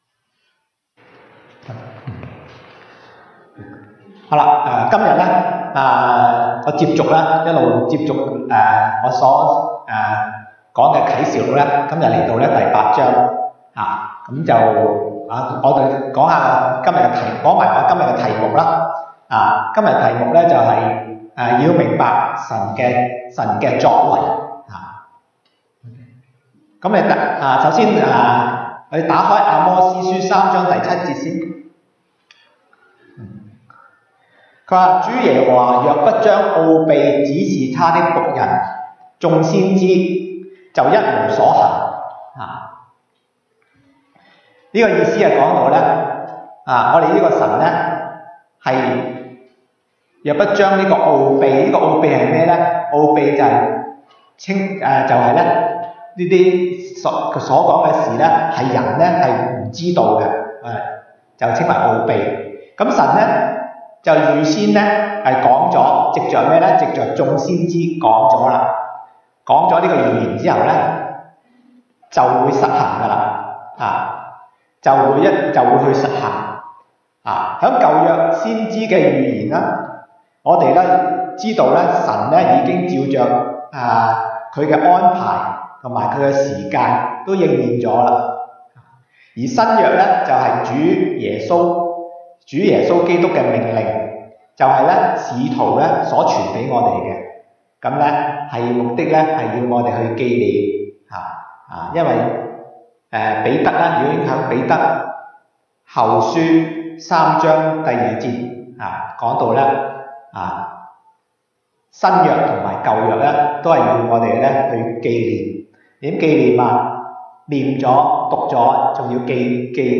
東北堂證道 (粵語) North Side: 要明白神的作為
Passage: 啟示錄 Revelation 8:1-13 Service Type: 東北堂證道 (粵語) North Side (First Church)